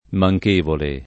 [ ma j k % vole ]